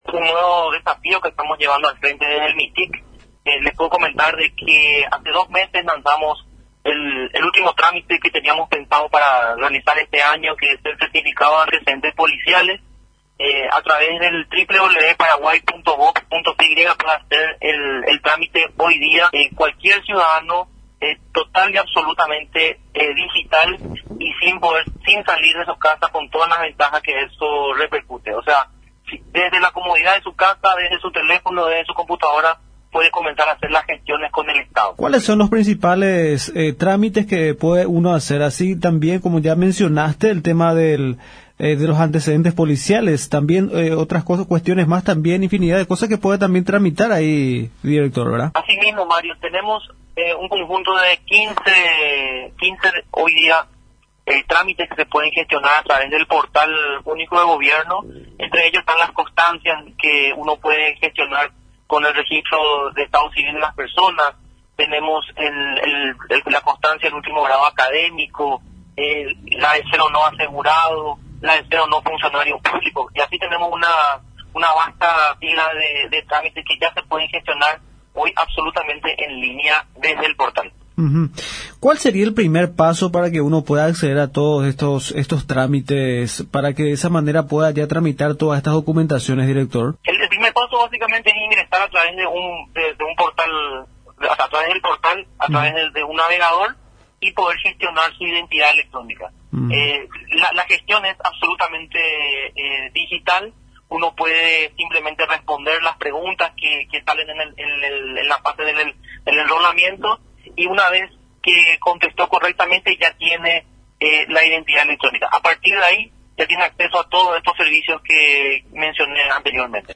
El Director general de gobierno electrónico, Klauss Pistilli, en conversación con Radio Nacional, dio a conocer de las principales acciones desarrolladas mediante el componente Gobierno Electrónico de la Agenda Digital, donde los ciudadanos tienen la posibilidad de realizar los trámites en línea de forma gradual.